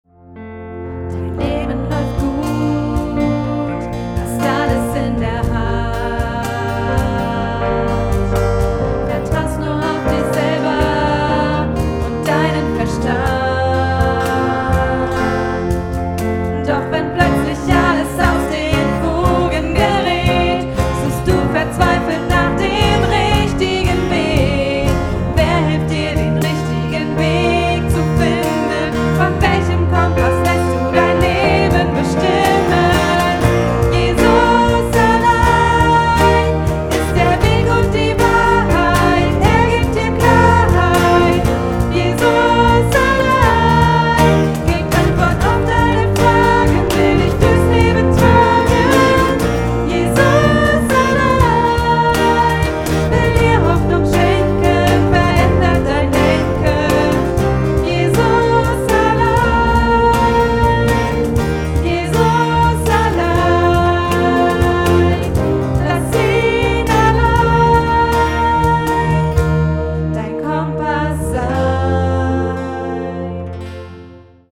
Notation: SATB
Tonart: Bb, Db
Taktart: 4/4
Tempo: 100 bpm
Parts: 2 Verse, Refrain
Noten, Noten (Chorsatz)
Worship, Liedvortrag